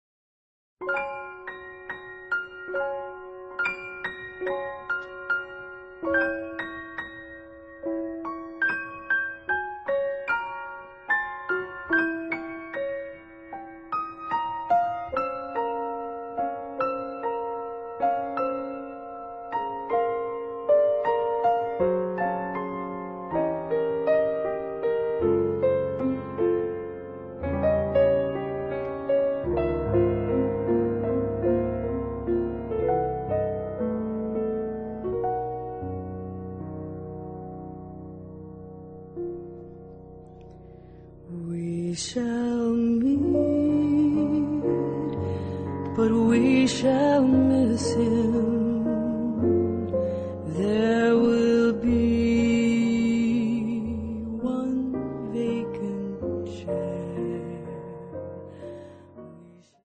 piano, voice
understated avant-garde.